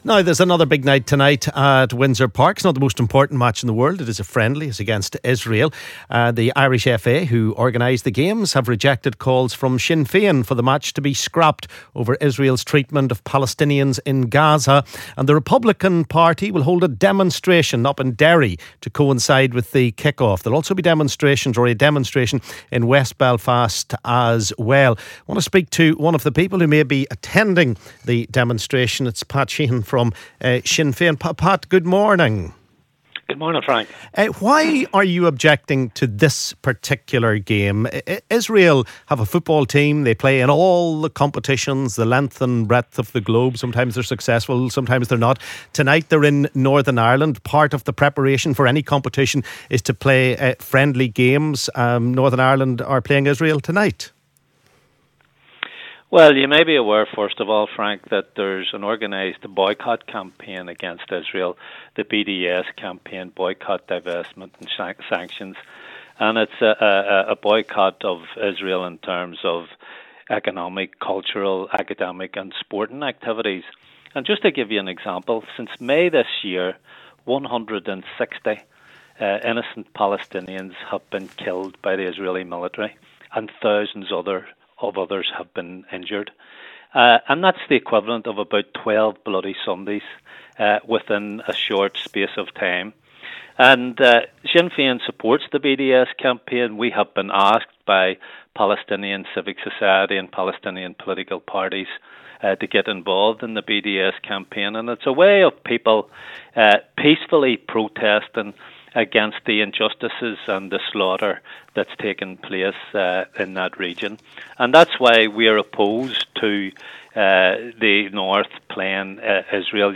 LISTEN ¦ Callers and politicians clash ahead of Israel v NI match protests